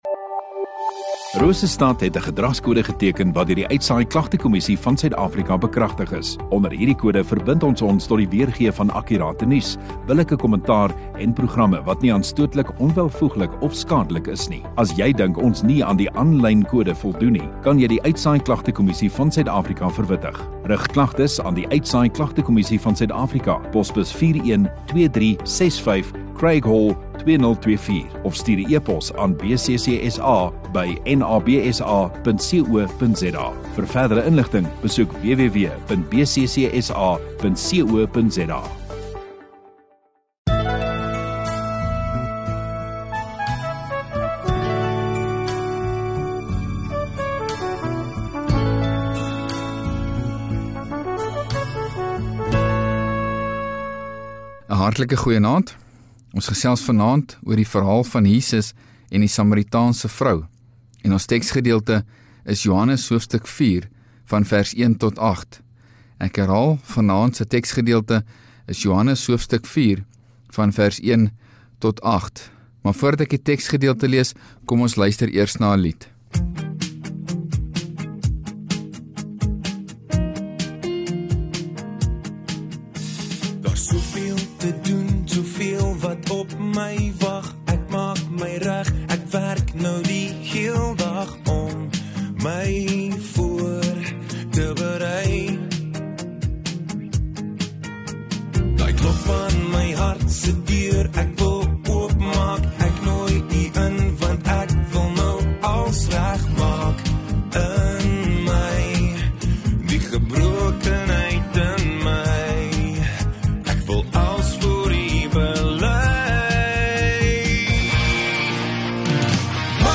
14 Sep Sondagaand Erediens